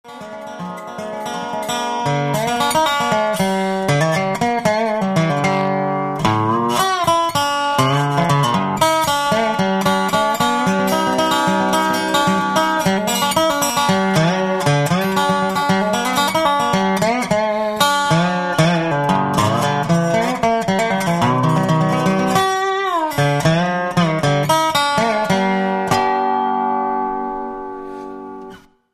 Dobro Beispiel 1
dobro1.mp3